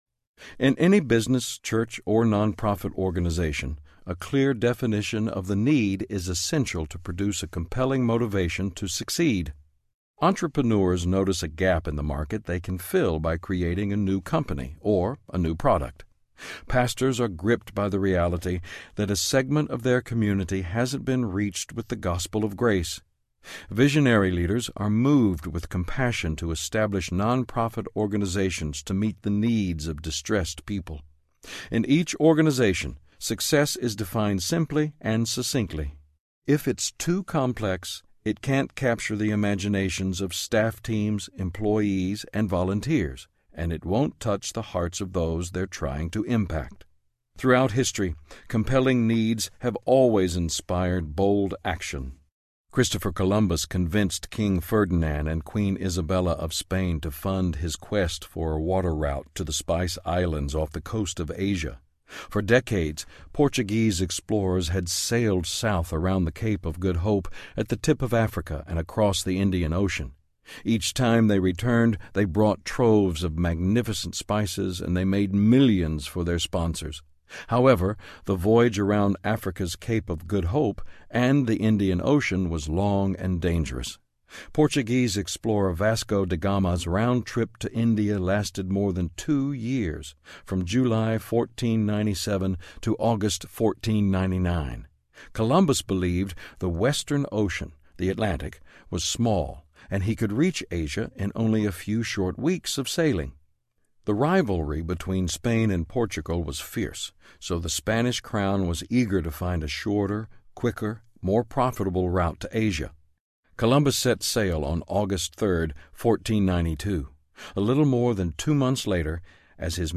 Bigger, Faster Leadership Audiobook
Narrator